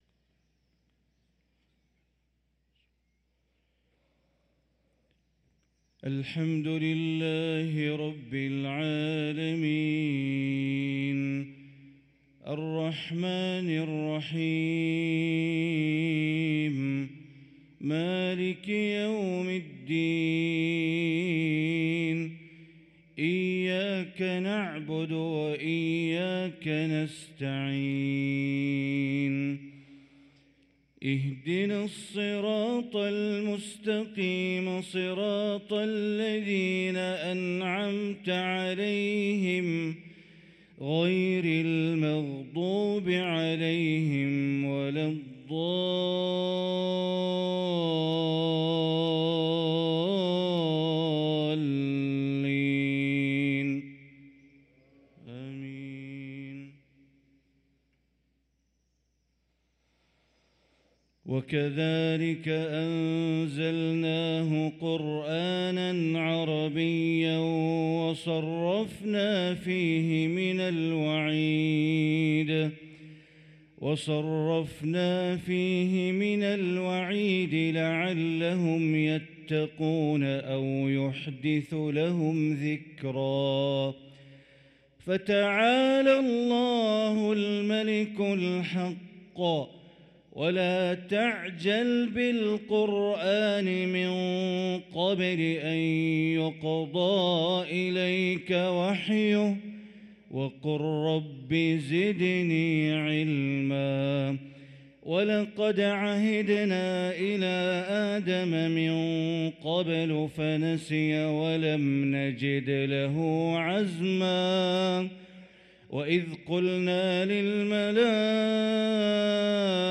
صلاة الفجر للقارئ بندر بليلة 15 جمادي الأول 1445 هـ